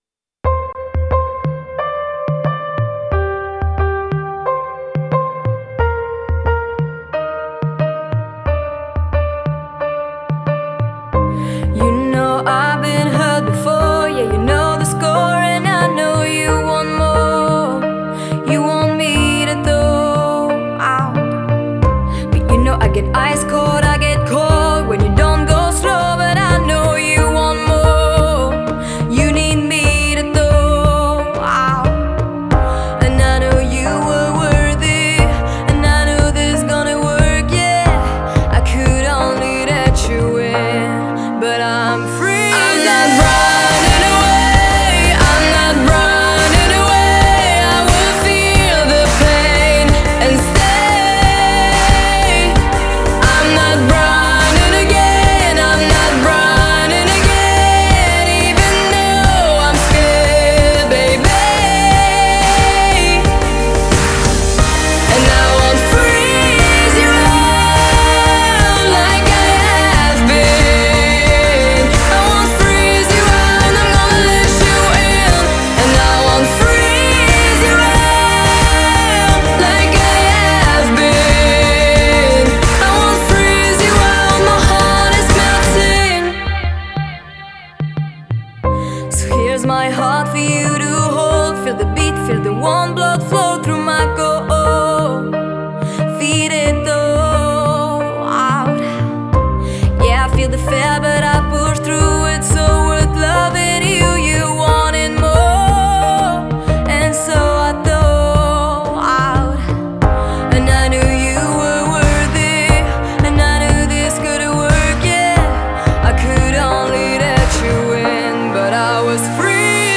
Genre: Pop, Female vocalists